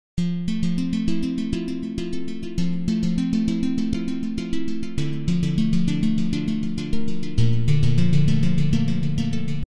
Tag: 100 bpm Classical Loops Guitar Electric Loops 1.62 MB wav Key : Unknown